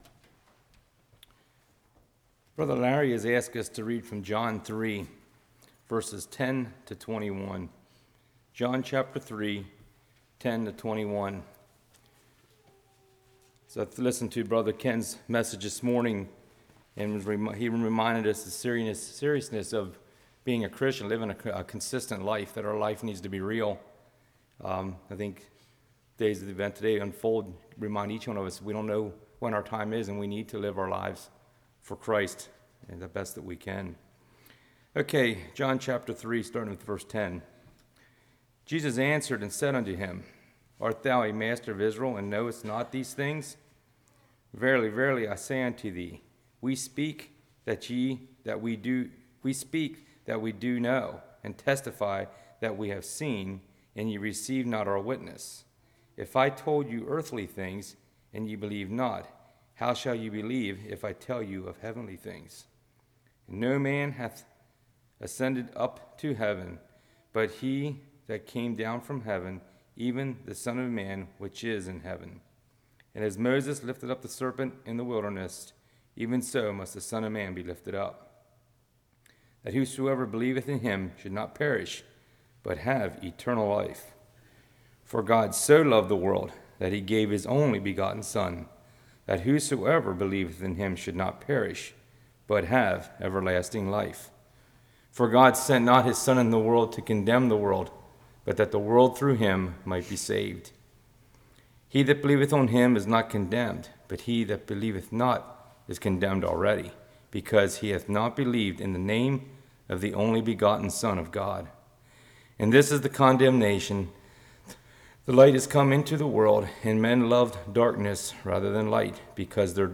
John 3:10-21 Service Type: Evening Light Shines Light Reflects Light Illuminates « Depression